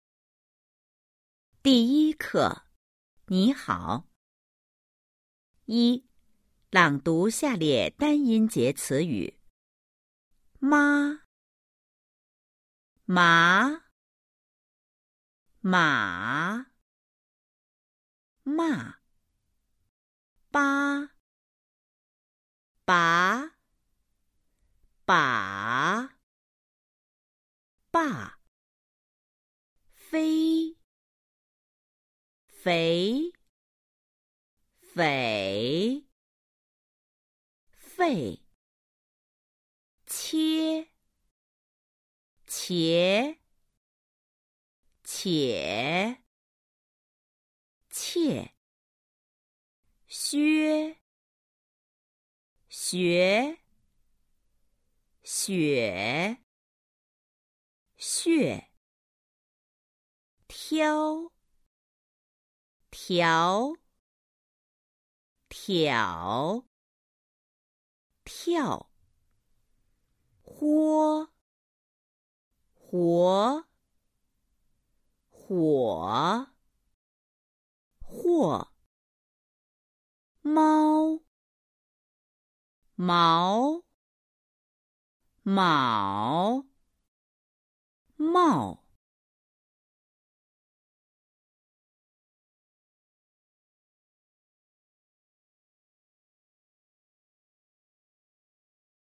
Đọc to các từ có một âm tiết dưới đây.